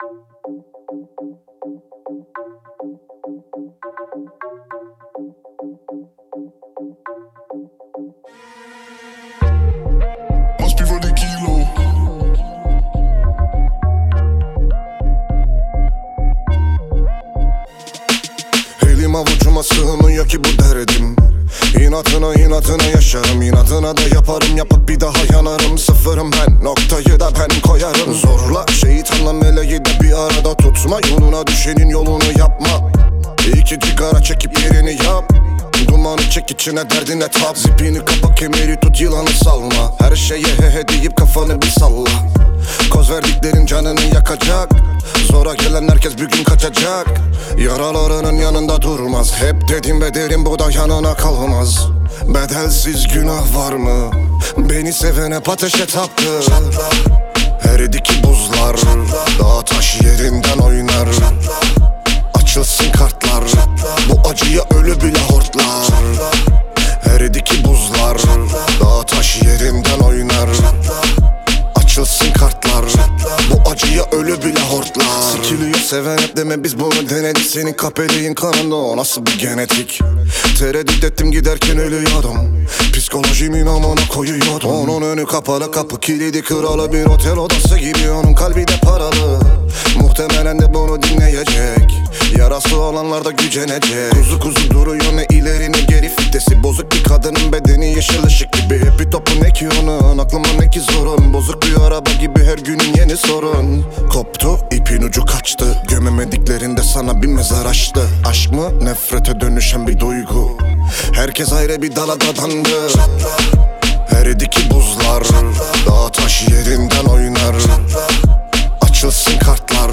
آهنگ رپ ترکی بیس دار
آهنگ بیس دار خارجی